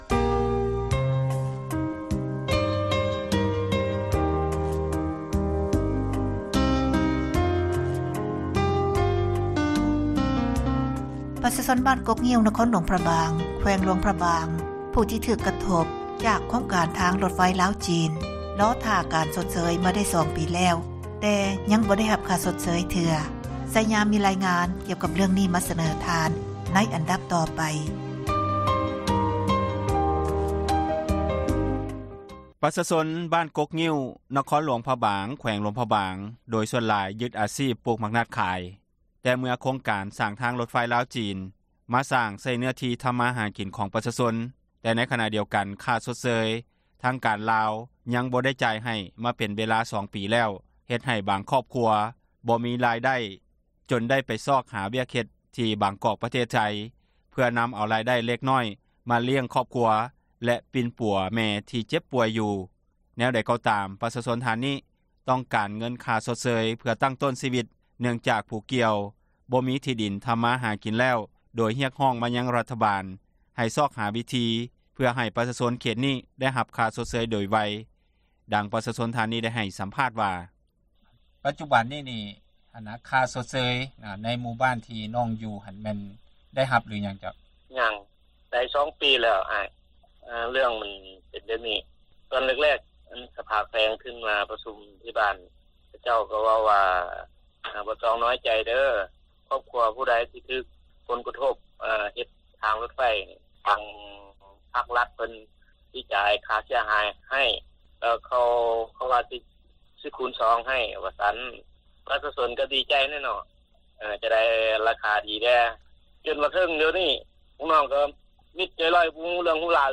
ແນວໃດກໍ່ຕາມປະຊາຊົນ ທ່ານນຶ່ງທີ່ຕ້ອງການເງີນຄ່າຊົດເຊີຍ ເພື່ອຕັ້ງຕົ້ນຊີວິດ ເນື່ອງຈາກຜູ້ກ່ຽວ ບໍ່ມີທີ່ດິນທຳກິນແລ້ວ ໂດຍຮຽກຮ້ອງມາ ຍັງຣັຖບານໃຫ້ຊອກຫາວິທີຊ່ອຍປະຊາຊົນເຂດນີ້ ໃຫ້ໄດ້ຮັບຄ່າຊົດເຊີຍໂດຍໄວ ດັ່ງປະຊາຊົນທ່ານນີ້ໄດ້ໃຫ້ ສຳພາດວ່າ: ເຊີນຟັງການສຳພາດ...